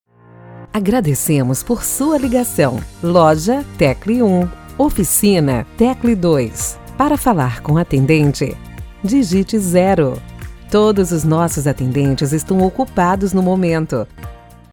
LOCUÇÃO INSTITUCIONAL :
URA / ESPERA TELEFONICO :
Padrão
Impacto
Animada
A voz é muito bonita!